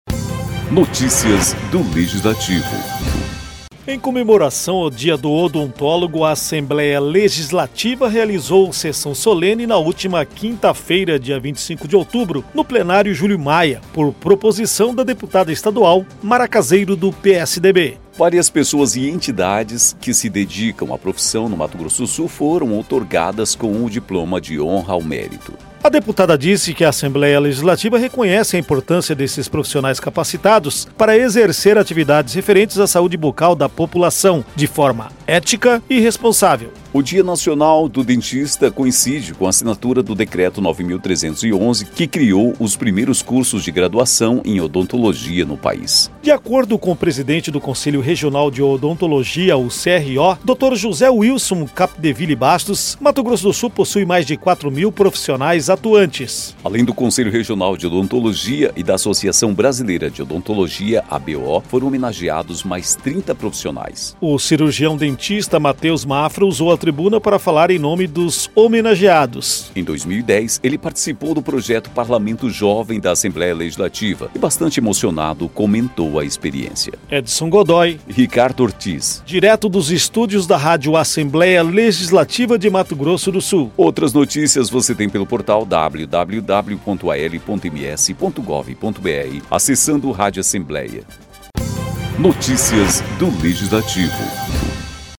Em sessão solene, Assembleia Legislativa celebra o Dia do Dentista